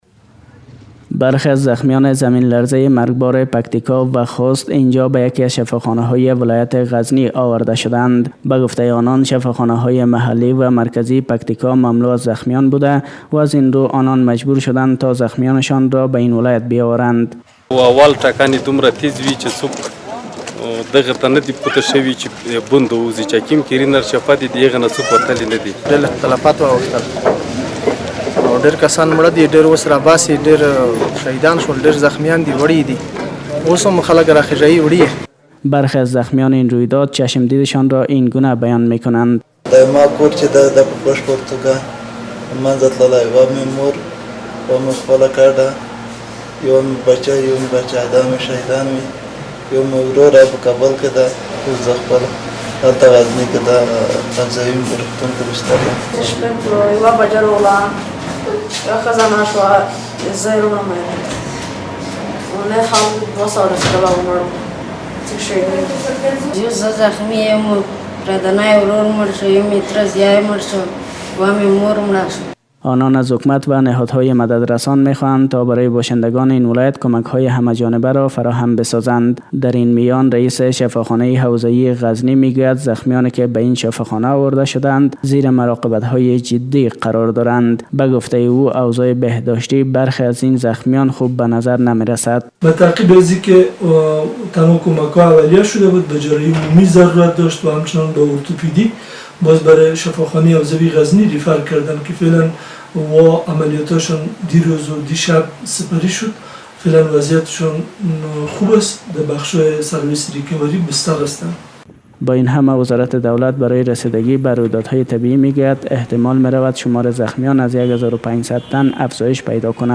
Токунӯн захмӣ шудани 1600 нафар дар зилзилаи Пактико таъйид шудааст. Садои теъдоде аз бастагони маҷрӯҳон